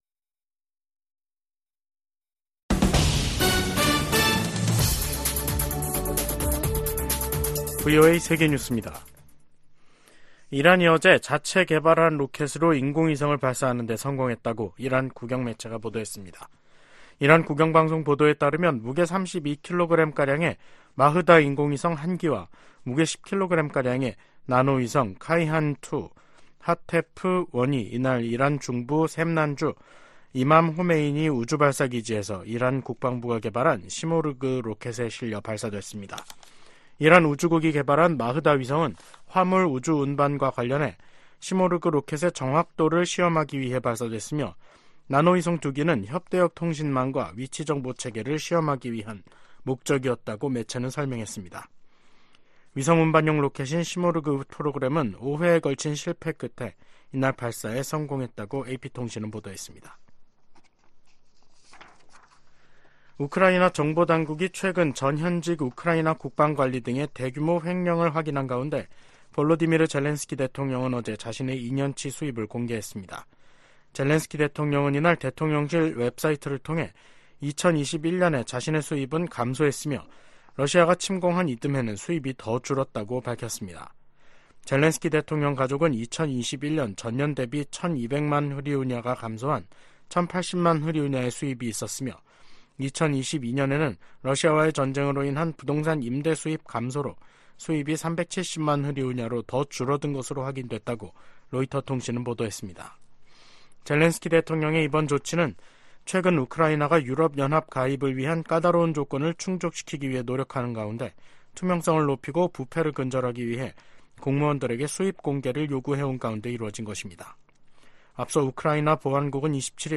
VOA 한국어 간판 뉴스 프로그램 '뉴스 투데이', 2024년 1월 29일 2부 방송입니다. 북한은 어제 시험발사한 미사일이 새로 개발한 잠수함발사 순항미사일이라고 밝혔습니다. 제이크 설리번 미국 국가안보보좌관이 왕이 중국 외교부장에게 북한의 무기실험과 북러 협력에 대한 우려를 제기했다고 미국 정부 고위당국자가 밝혔습니다. 백악관은 북한의 첨단 무기 능력 추구와 관련해 동맹국 보호 의지를 재확인했습니다.